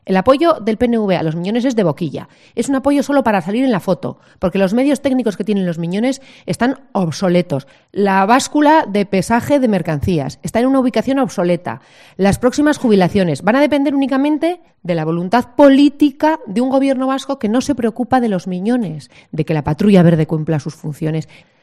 Ana Morales, juntera del PP alavés, sobre los Miñones